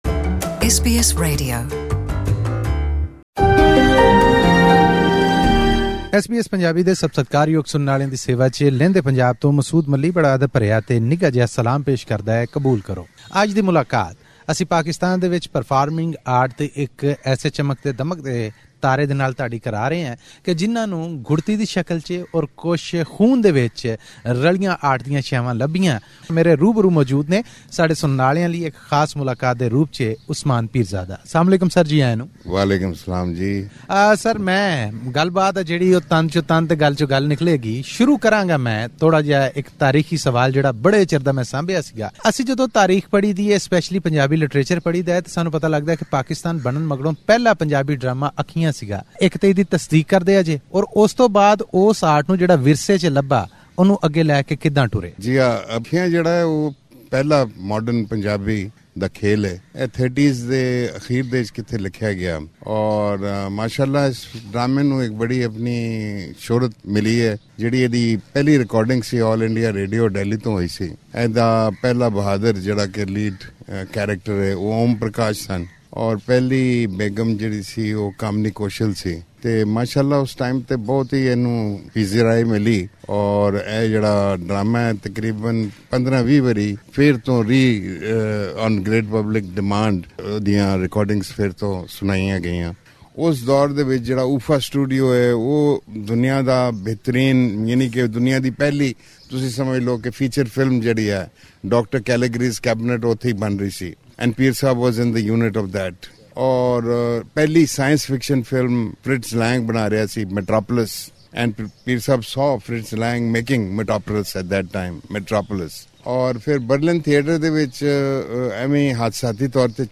Every fortnight, we interview a well-known personality from Pakistan's Punjab province.